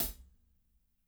-18  CHH R-R.wav